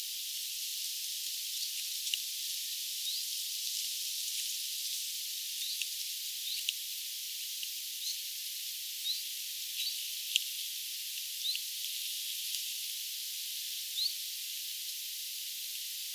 vit-tiltaltti kauempaa
vit-tiltaltti_kauempaa.mp3